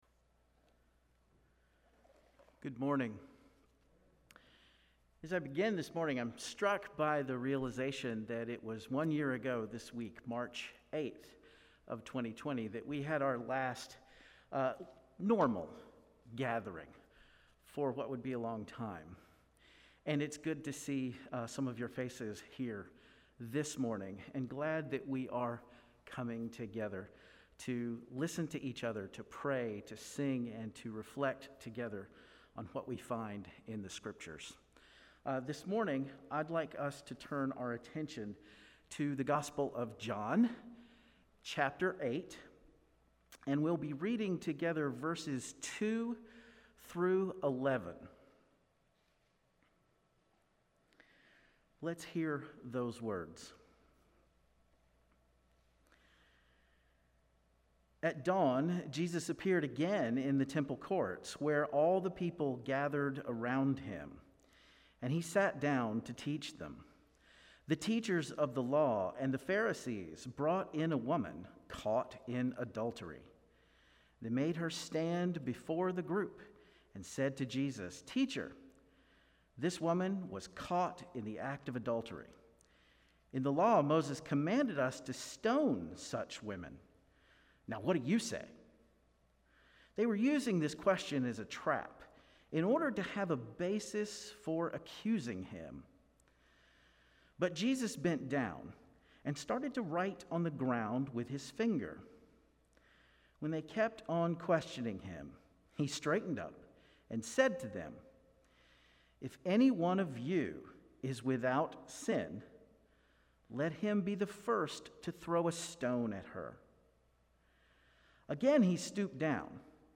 Passage: John 8:2-11 Service Type: Guest Preacher Bible Text